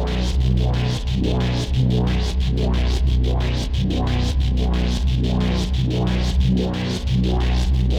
Index of /musicradar/dystopian-drone-samples/Tempo Loops/90bpm
DD_TempoDroneE_90-B.wav